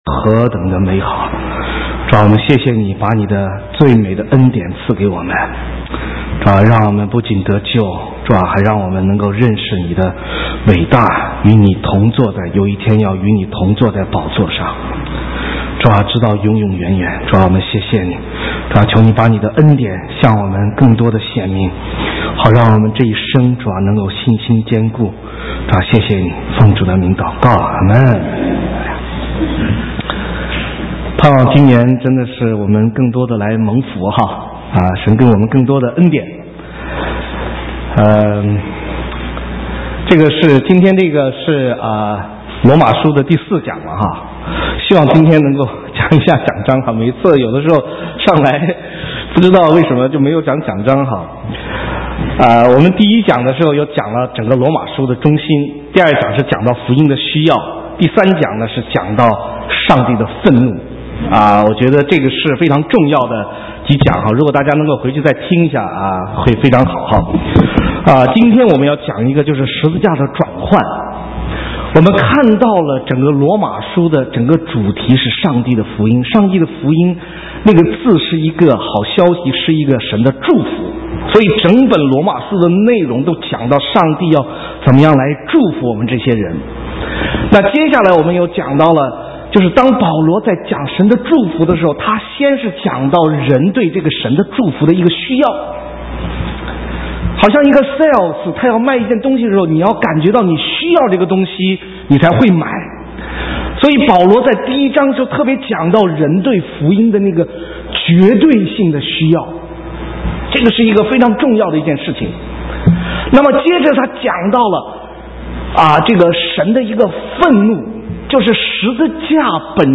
神州宣教--讲道录音 浏览：十字架的转换 (2012-01-15)